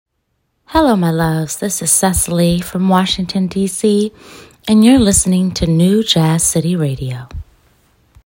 known for her agile soprano and honest lyrics